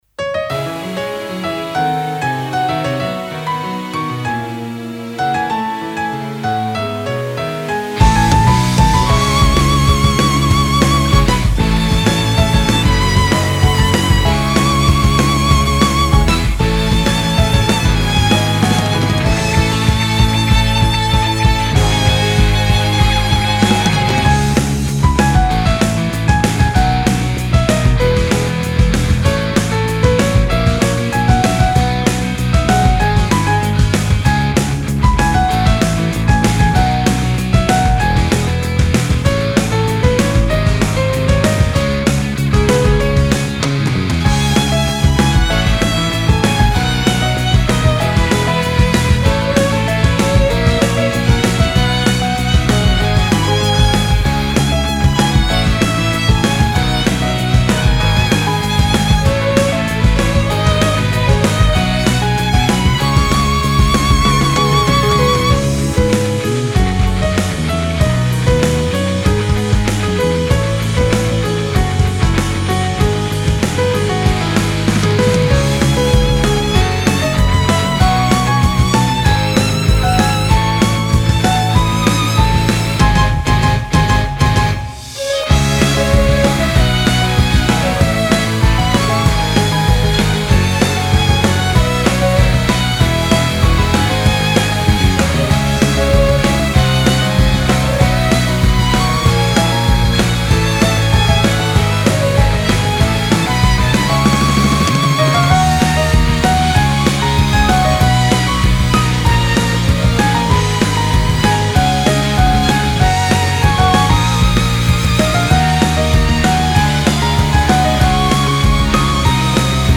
フリーBGM バトル・戦闘 バンドサウンド
フェードアウト版のmp3を、こちらのページにて無料で配布しています。